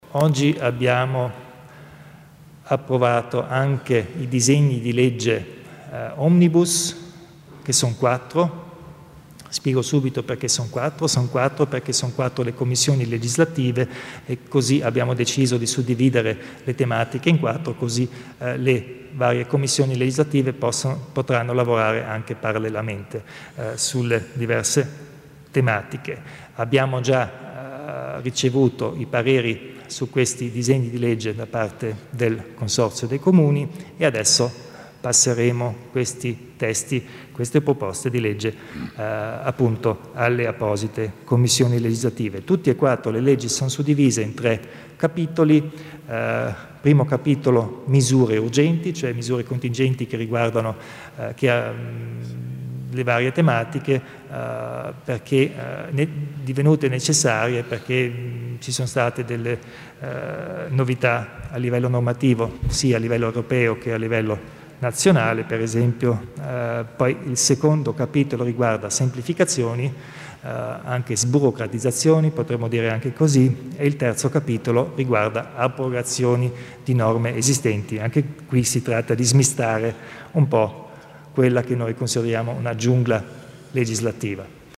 Il Presidente Kompatscher illustra i dettagli della legge omnibus